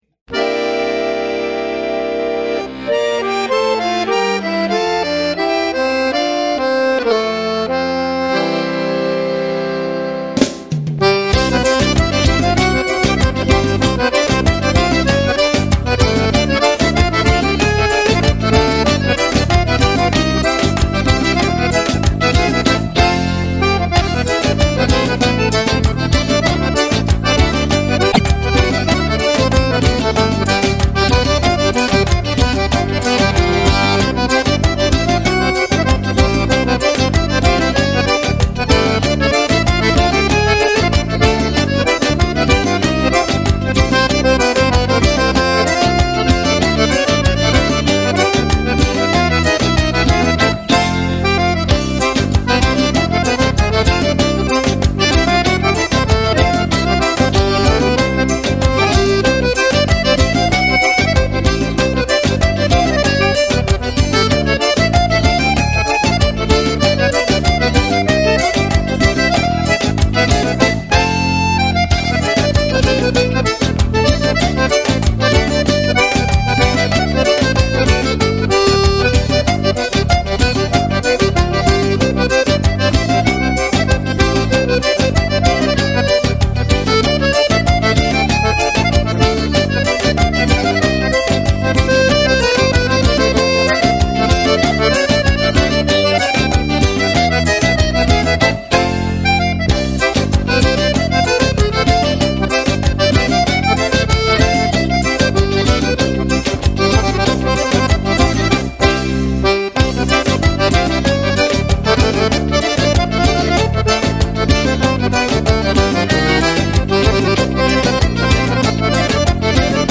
Sanfona